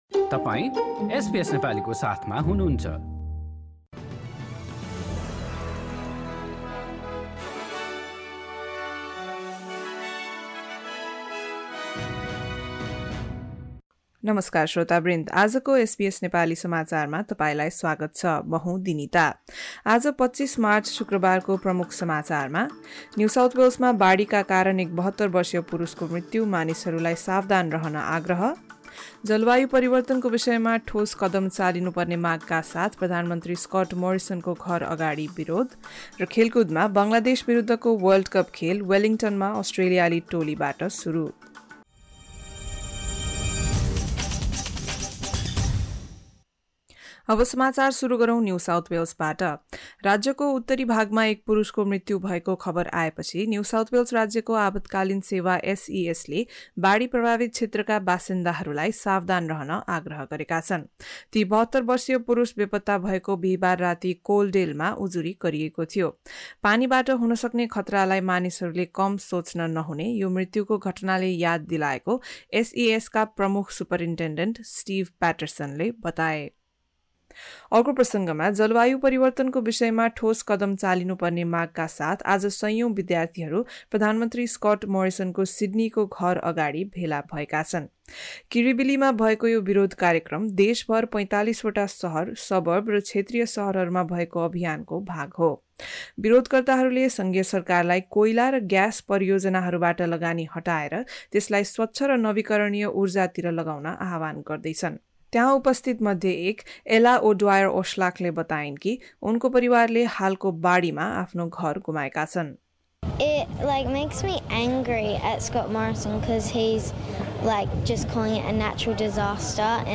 एसबीएस नेपाली अस्ट्रेलिया समाचार: शुक्रबार २५ मार्च २०२२